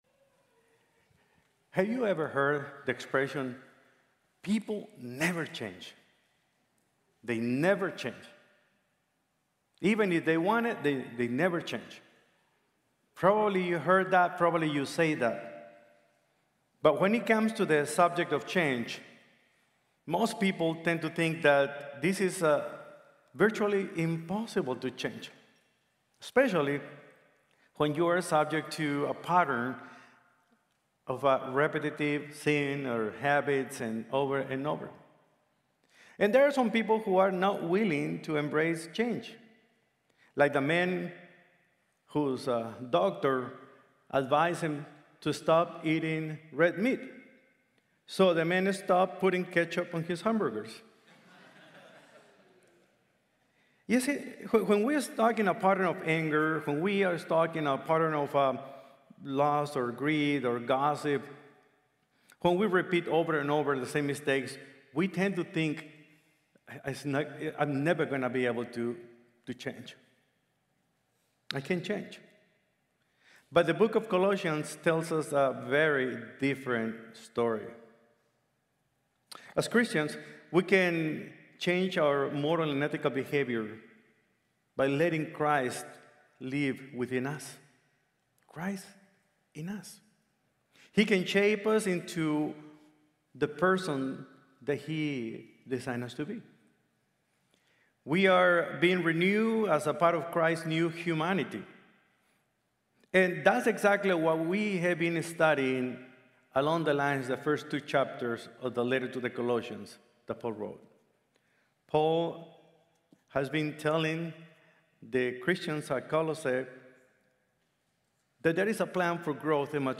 Christ in You | Sermon | Grace Bible Church